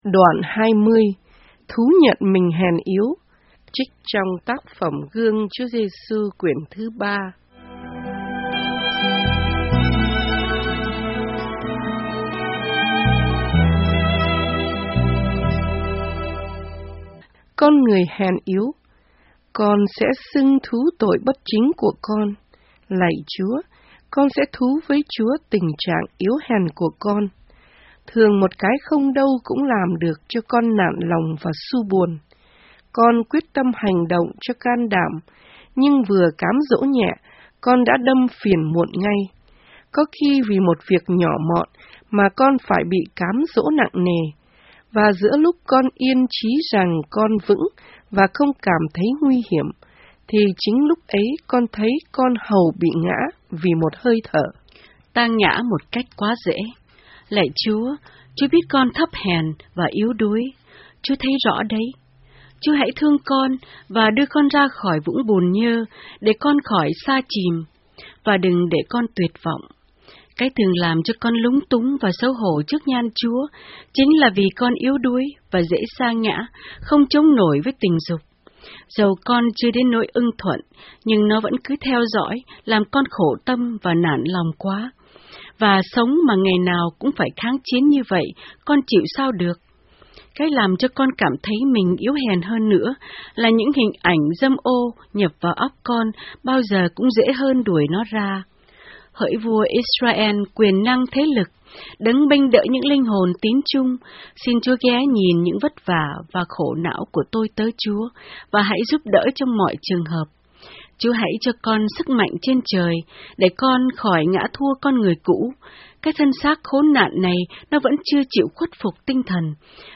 Audio Book Guong Chua Giesu Cuon 3 :: Memaria